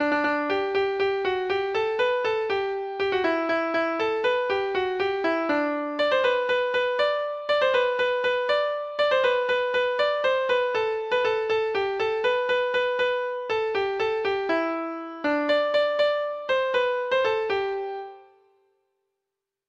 Folk Songs